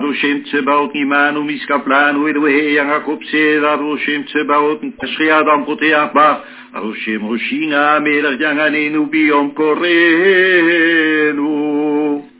Mitsva (funeral)
If there are no Avelim: Chazzan